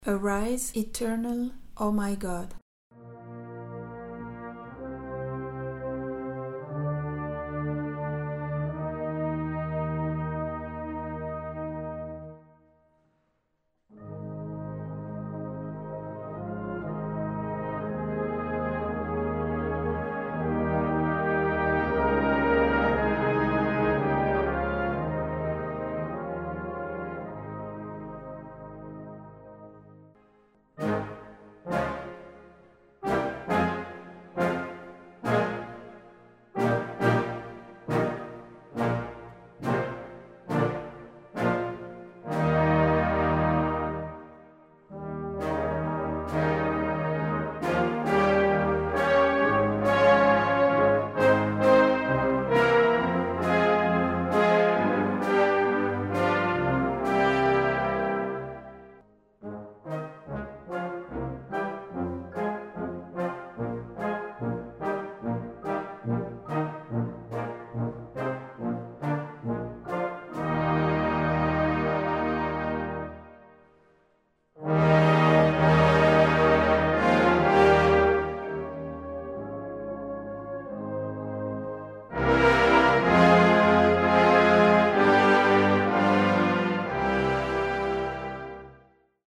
Brass Band
Warm Up / Echauffement